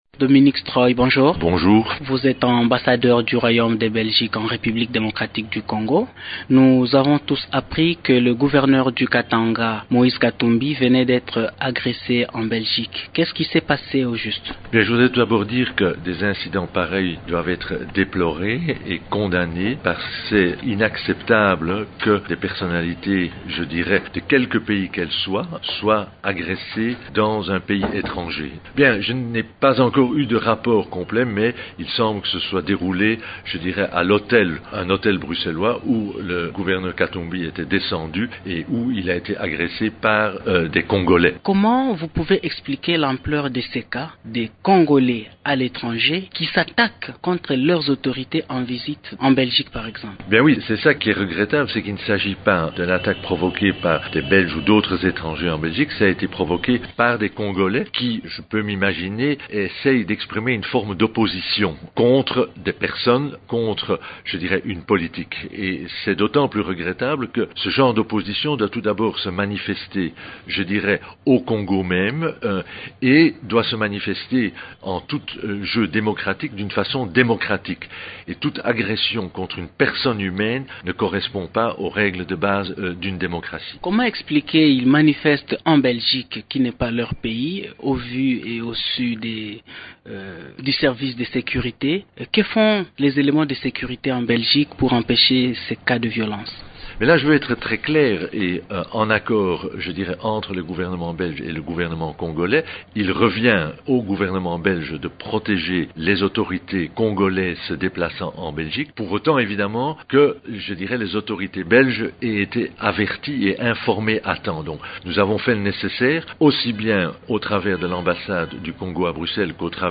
L’ambassadeur de Belgique en RDC, Dominique Struye de Swielande est interrogé